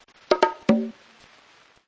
Congas sound
(This is a lofi preview version. The downloadable version will be in full quality)